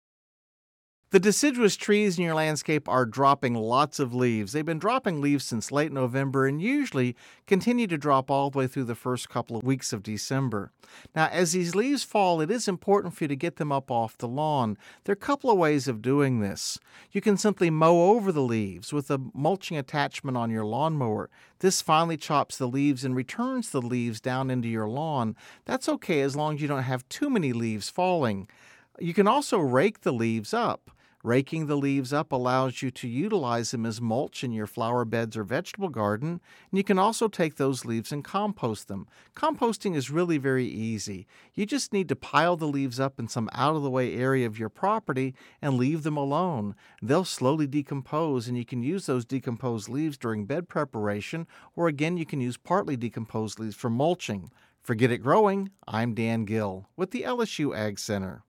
(Radio News 12/13/10) Deciduous trees in our landscapes have been dropping leaves. Get these leaves off the lawn and consider using them as mulch or compost.